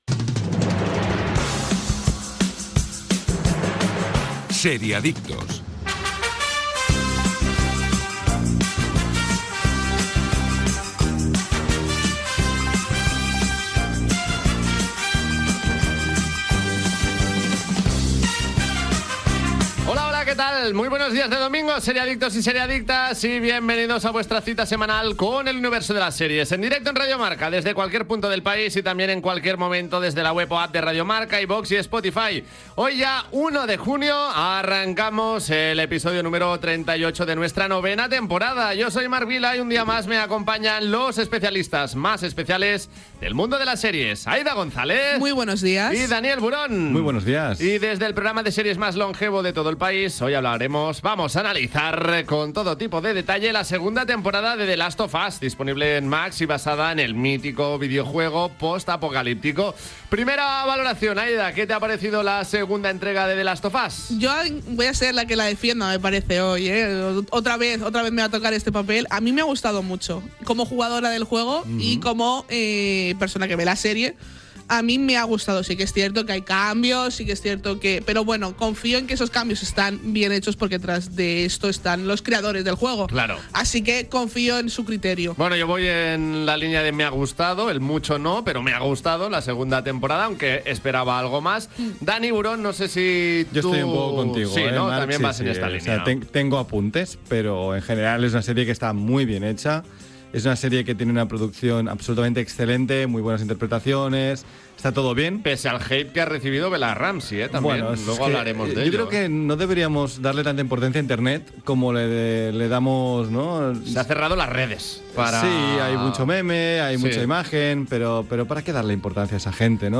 Careta del programa, presentació, salutació als col·laboradors, la sèrie "The last of us", publicitat
Entreteniment